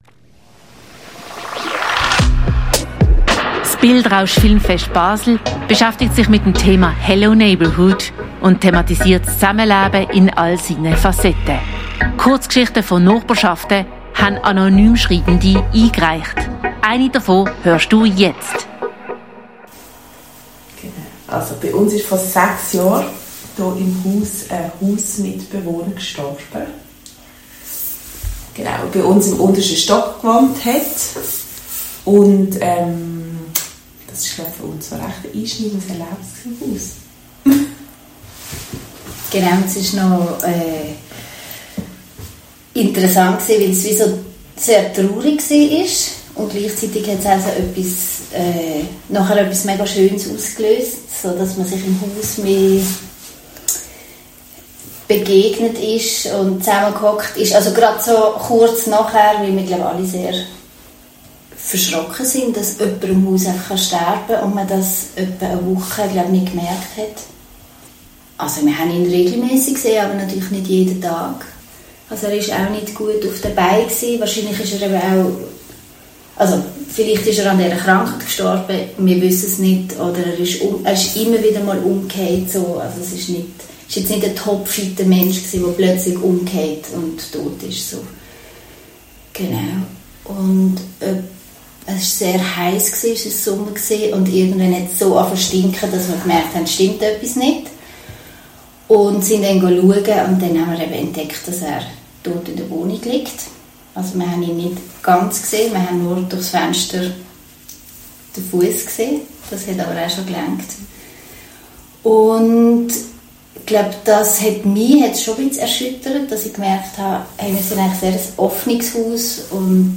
Kurzgeschichte Einsamer Tod ~ Spezialthemen Podcast